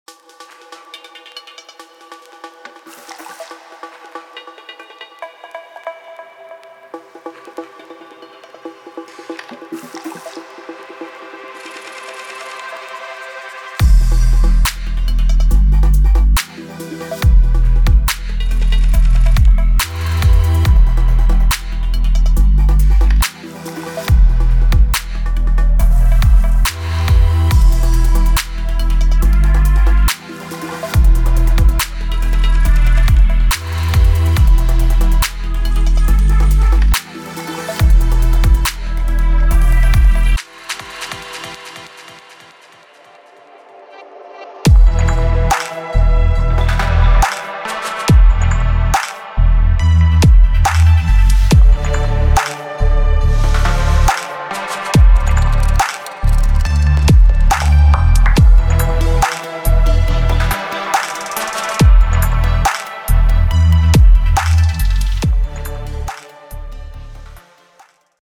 Chillout
Dreamy, and mellow vibes with emotive and catchy samples